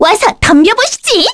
Reina-Vox_Skill2_kr.wav